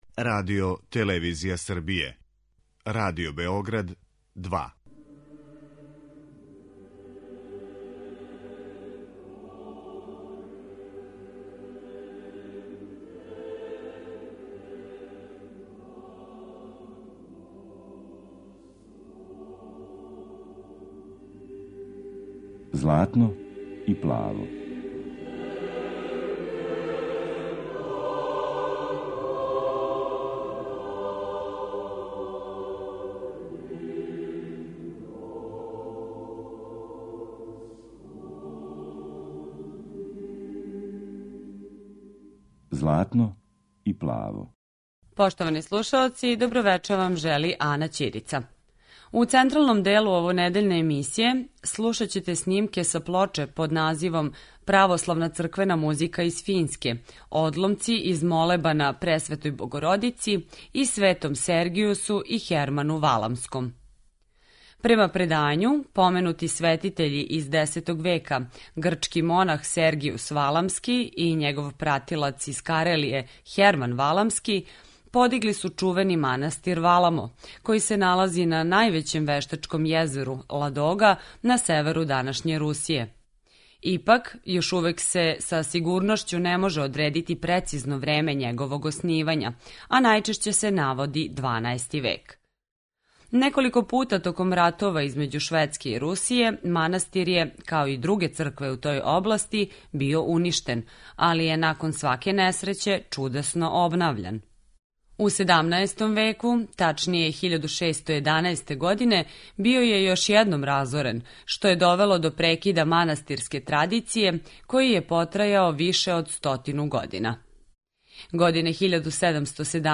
Емисија православне духовне музике